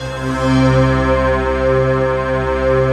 SI1 CHIME04L.wav